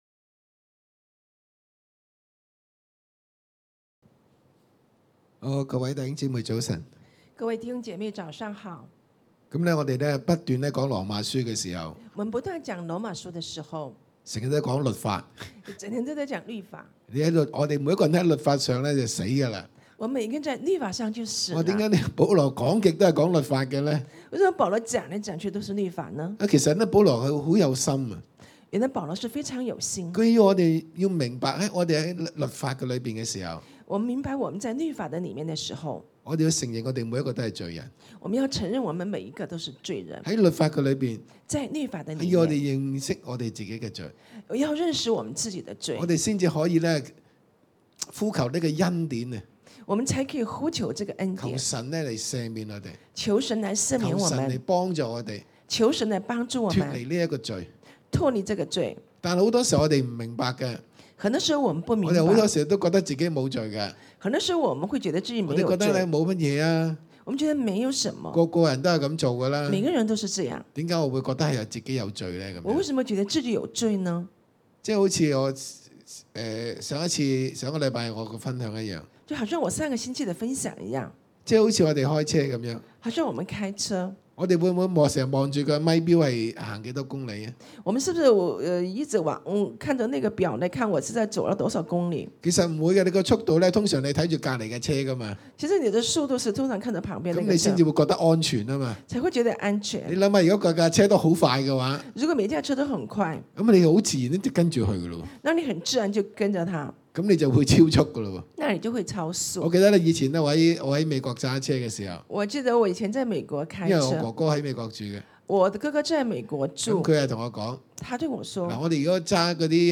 弟兄姊妹來到台前的十字架，呼喊耶穌的名字，向主傾吐心中的苦情。